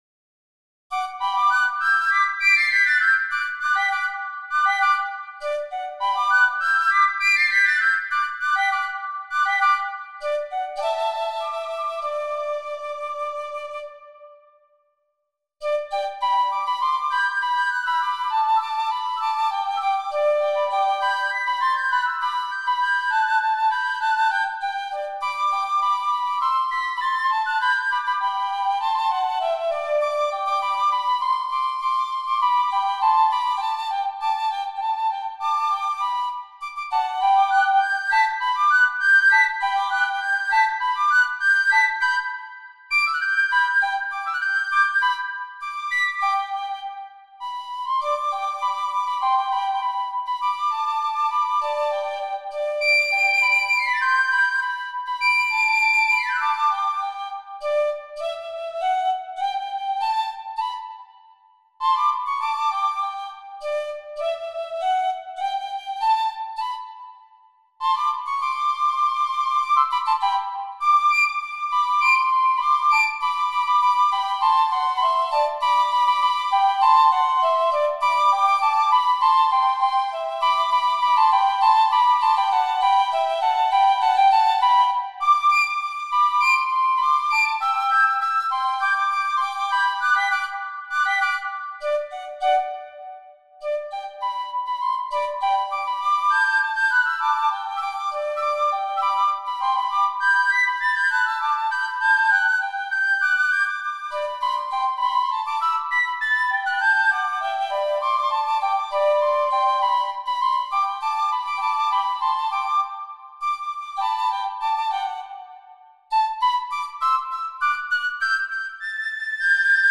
Piccolo Duos on Irish Themes